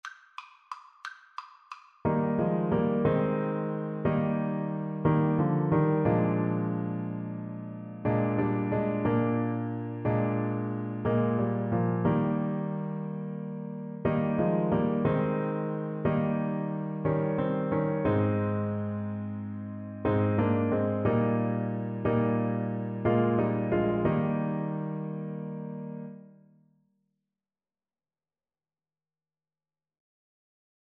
Christian
Alto Saxophone
9/4 (View more 9/4 Music)
Classical (View more Classical Saxophone Music)